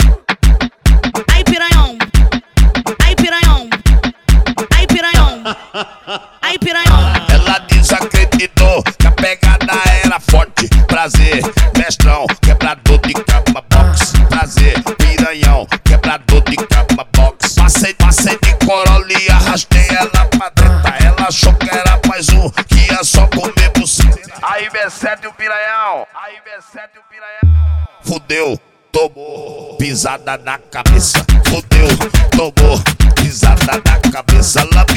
Жирный бас-клава и хлопковые гитары
Baile Funk Brazilian
Жанр: Фанк